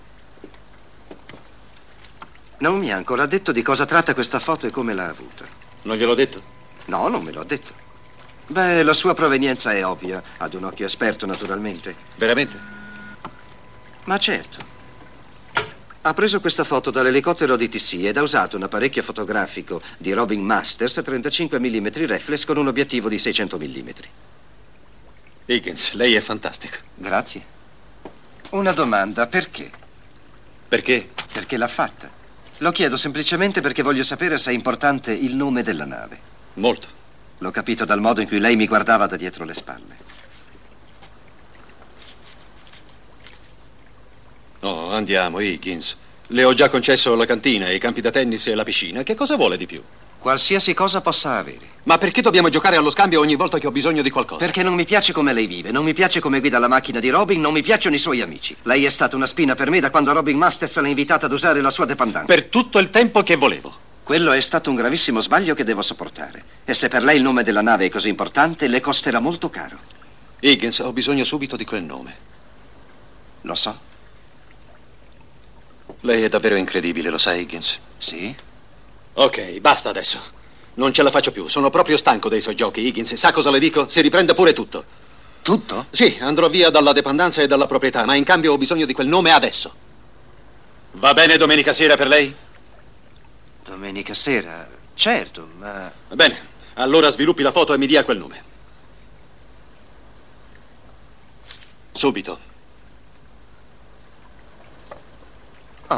nel telefilm "Magnum P.I.", in cui doppia John Hillerman.